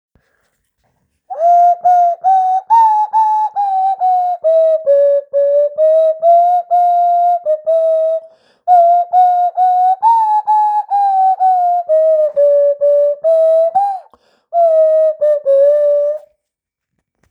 Hier ein kleines Meisterwerk eines "Parkbänklers" mit der Nasenflöte gespielt.